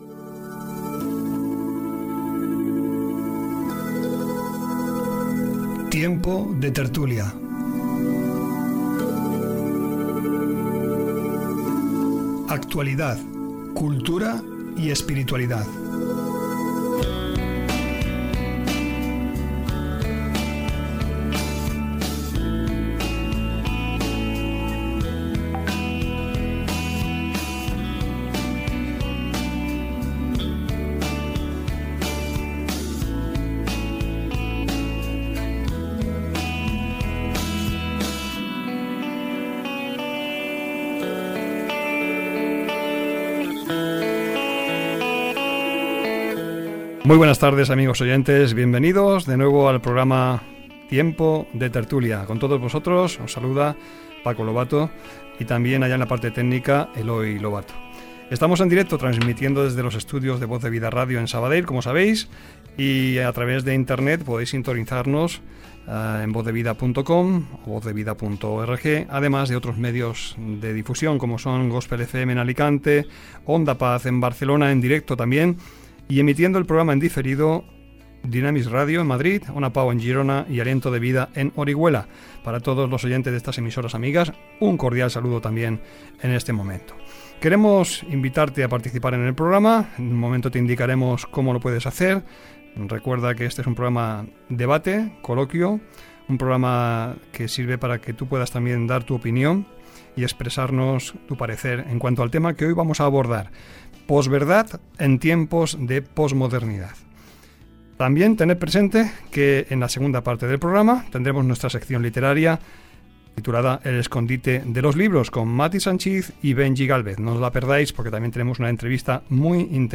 Identificació del programa, presentació, emissores que emeten el programa, sumari enquesta, formes de participar-hi invitats a la tertúlia dedicada a la postveritat en temps de postmodernitat